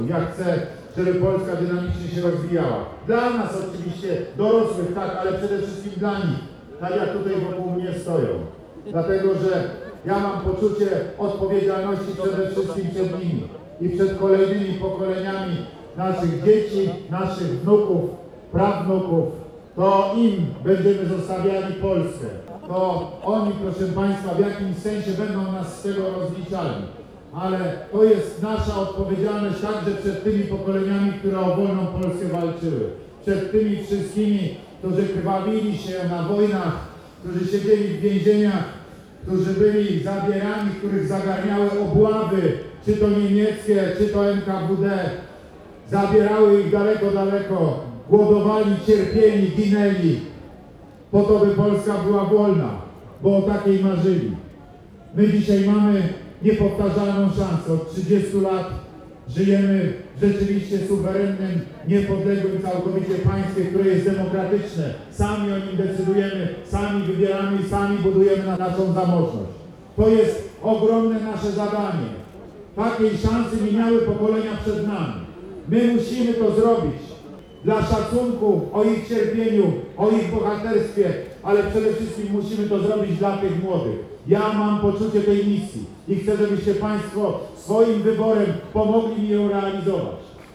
Na spotkanie w Parku Konstytucji 3 Maja przybyło wiele osób, także z regionu.